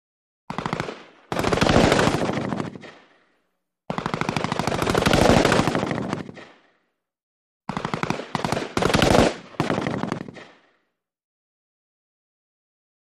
Automatic Burst: By ( 3x ); Doppler Effect Of Firing Machine Gun While Running. Five Separate Bys With Various Length Bursts. Heavy Low End Shots With Some Exterior Echo. Gunshots.